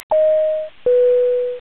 ding_dong.wav